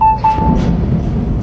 • BEEPS AND WIND.wav
BEEPS_AND_WIND_mOL.wav